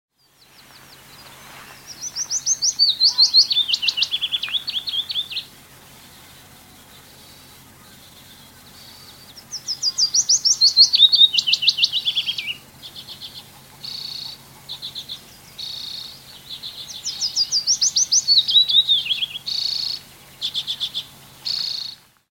Willow warbler song call Løvsanger sound effects free download